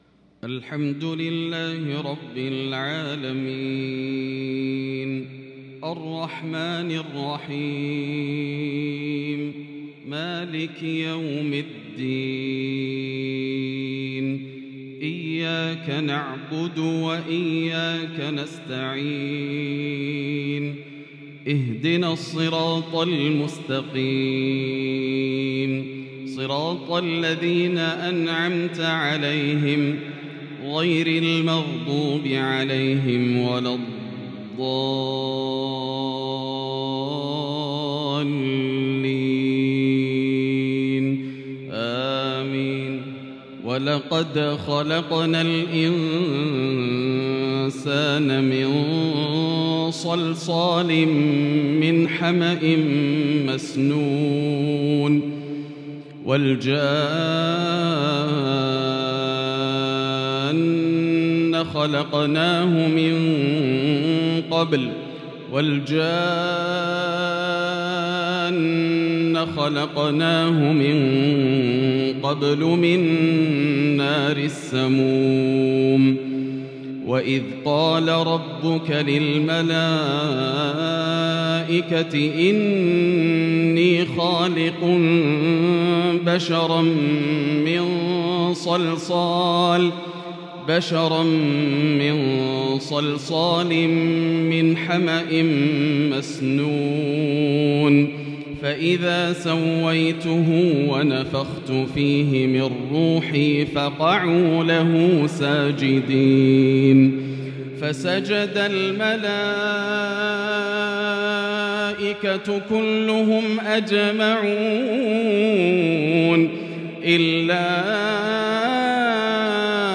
عشاء الخميس 29 شوال 1442هــ | من سورة الحجر | Isha prayer from Surat Al-Hijr 9-6-2021 > 1442 🕋 > الفروض - تلاوات الحرمين